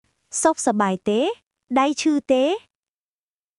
解説→→（ソック（良い） サバーイ（元気） テー（ですか？） ダイ（手） チュー（痛い） テー（ですか？））
当記事で使用された音声（クメール語および日本語）は全てGoogle翻訳　および　Microsoft TranslatorNative Speech Generation、©音読さんから引用しております。